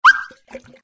SA_watercooler_appear_only.ogg